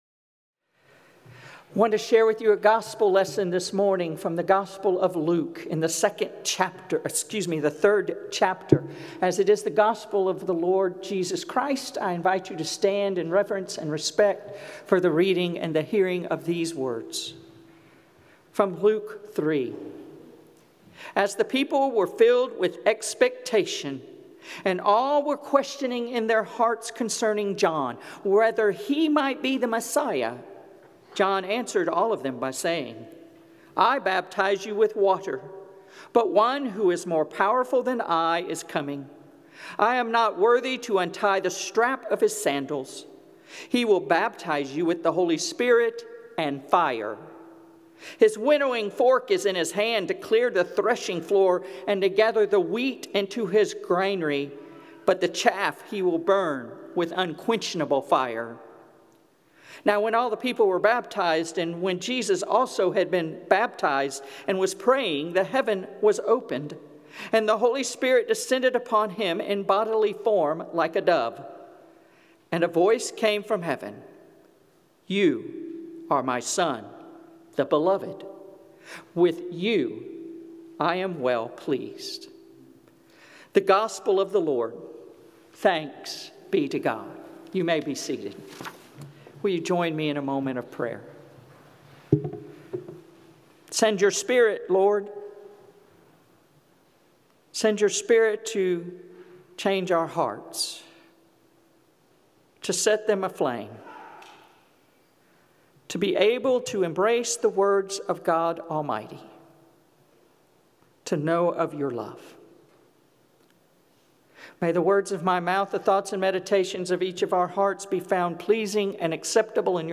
Sermons | Kingswood Church in Dunwoody, Georgia